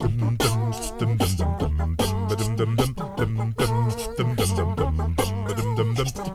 ACCAPELLA10C.wav